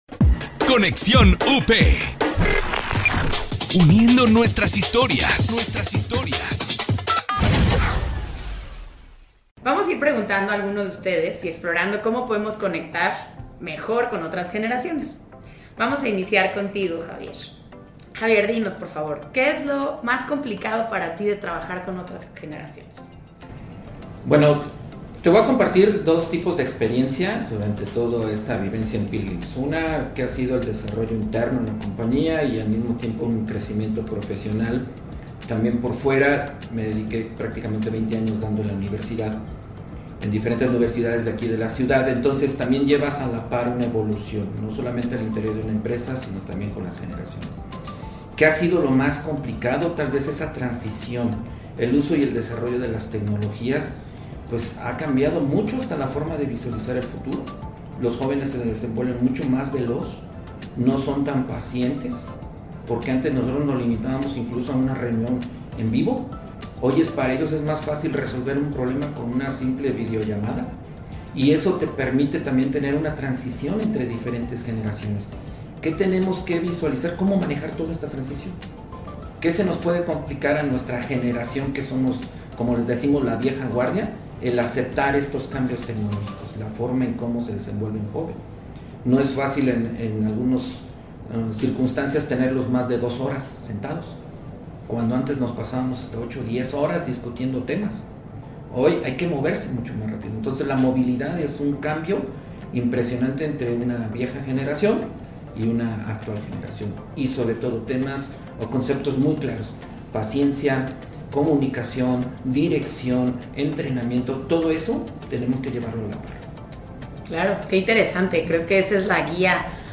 En este episodio reunimos a tres invitados representando a varias generaciones que pertenecen a la Gran Familia Pilgrim's.